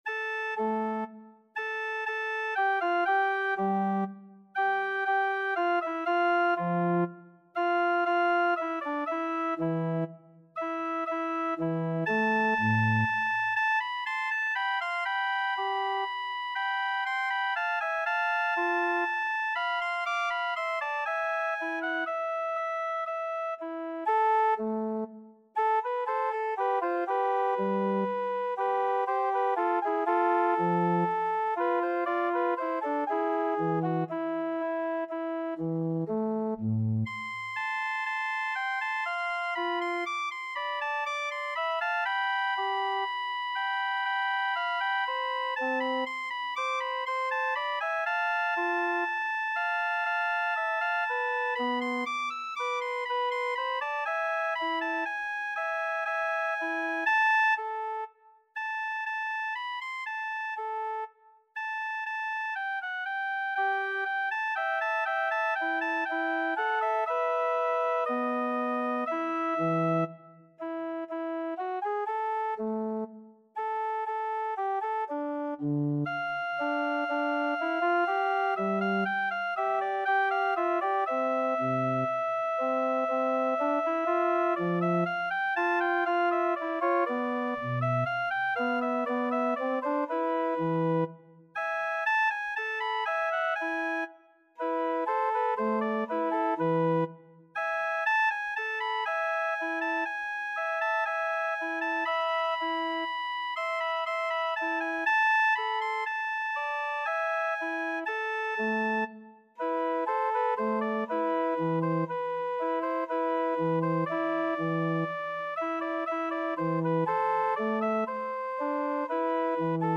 Play (or use space bar on your keyboard) Pause Music Playalong - Piano Accompaniment Playalong Band Accompaniment not yet available transpose reset tempo print settings full screen
A minor (Sounding Pitch) (View more A minor Music for Violin )
Adagio
3/4 (View more 3/4 Music)
Classical (View more Classical Violin Music)